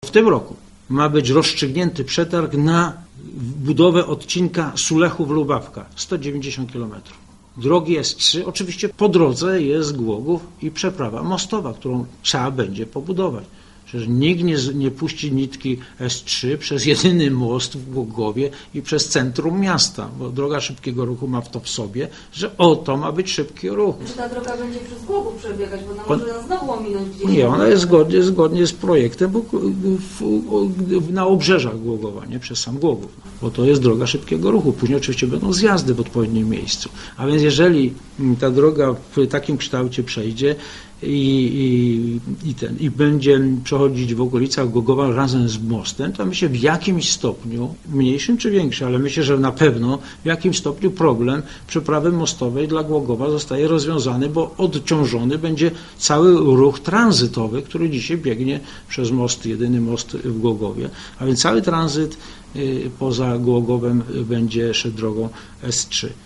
Cały tranzyt pójdzie przez S3, a nie przez jedyny w tej chwili most - mówił R. Zbrzyzny podczas spotkania z dziennikarzami.